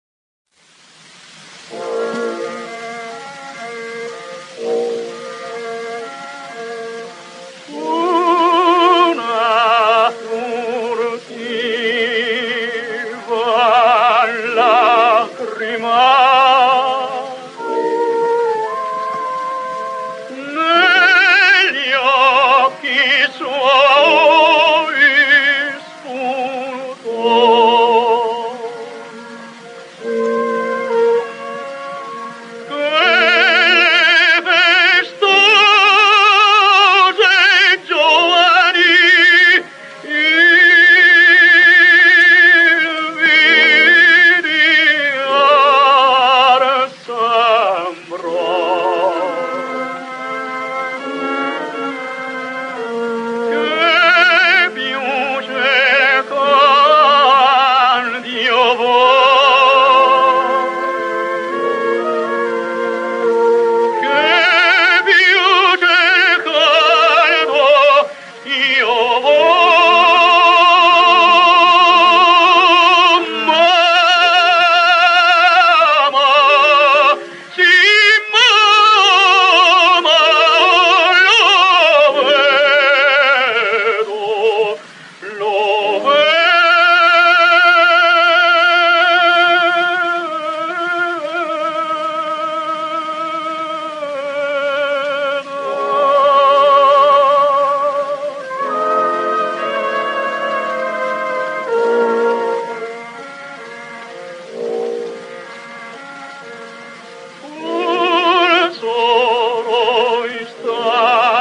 Bonci's high notes extended to a brilliant and ringing C-sharp, and possibly even D. His cadenzas and his ability to diminish a single high long note added to his fame.
Alessandro Bonci sings L'elisir d'amore: